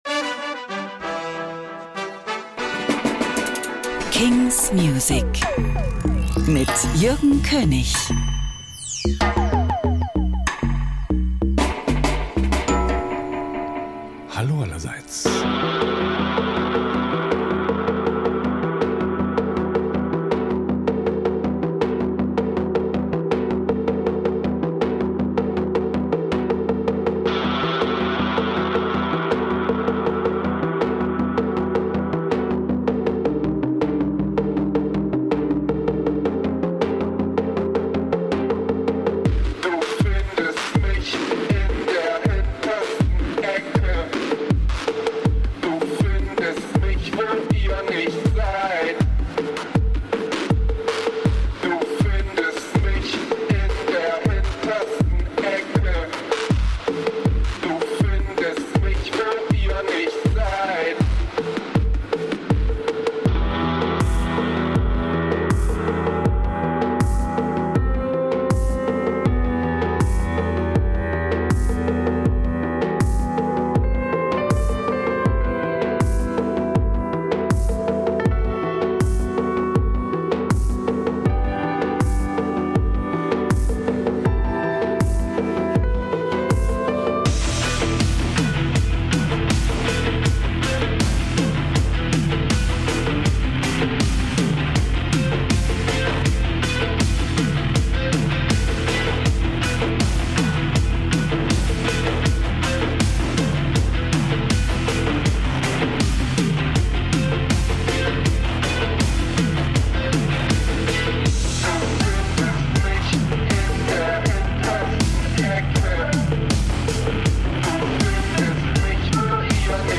indie & alternative releases